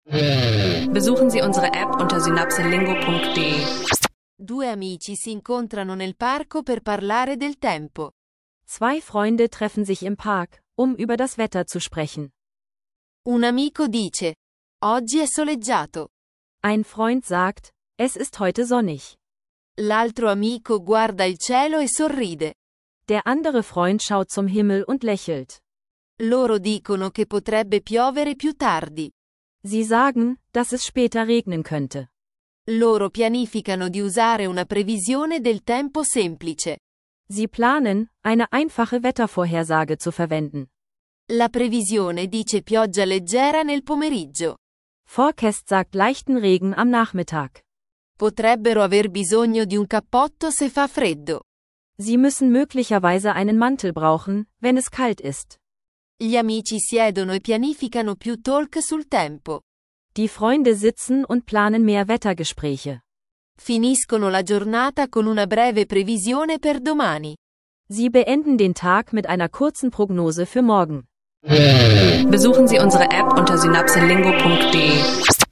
Zwei Freunde üben einfache Sätze zum Wetter und eine